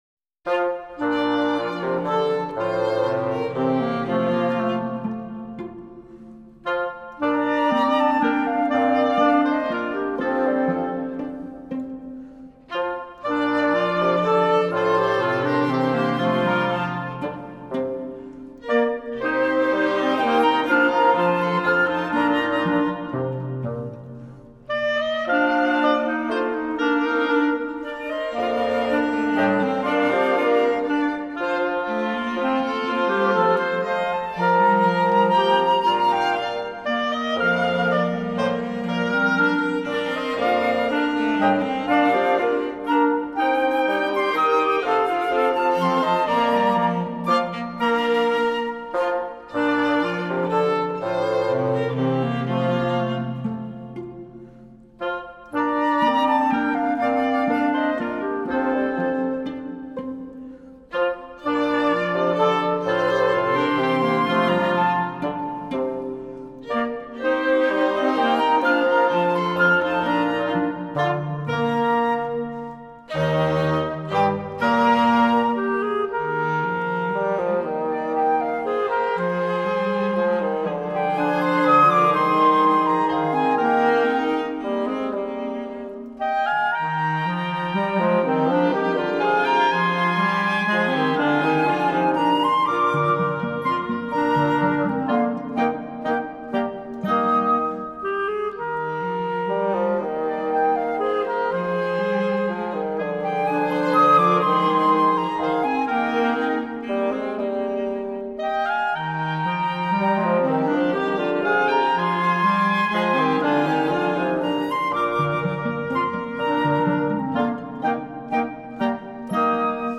Zurich Boys’ Choir – The most beautiful Swiss folk songs and tunes (Vol. 2)
Fabian (Walzer)
Accompanied by The Ludus Ensemble